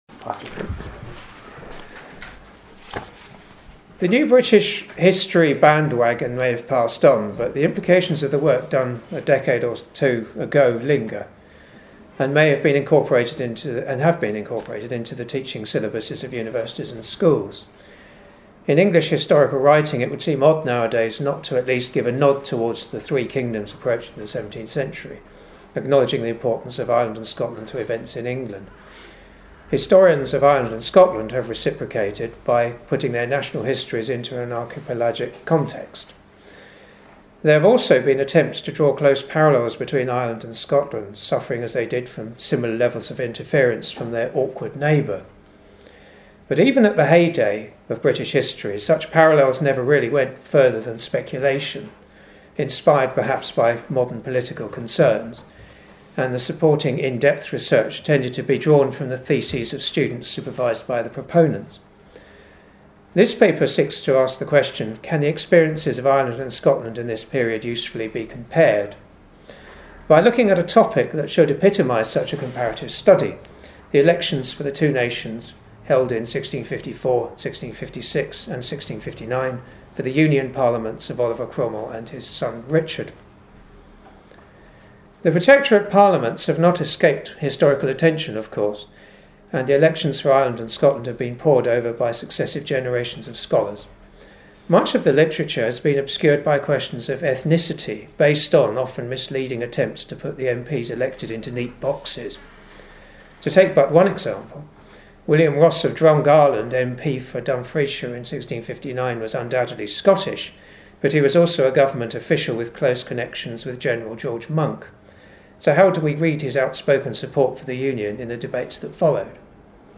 USIHS Lecture